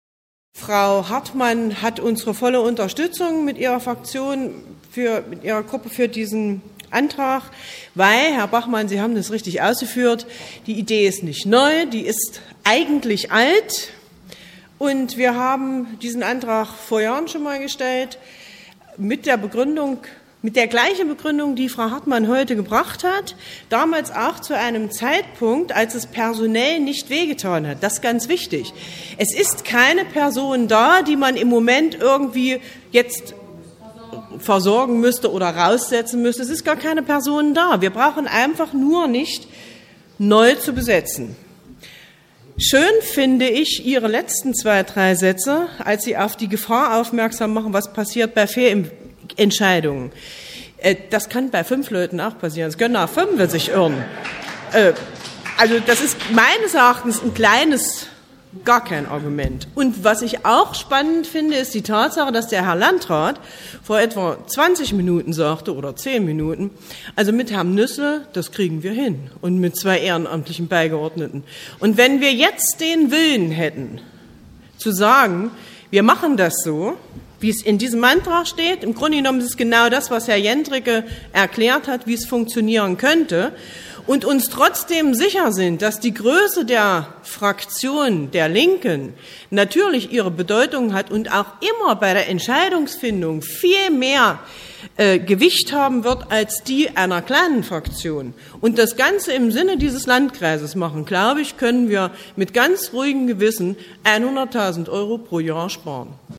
Wir haben hier die Wortmeldungen als Audiobeiträge für Sie zusammengestellt, die uns freundlicherweise das Bürgerradio ENNO zur Verfügung gestellt hat.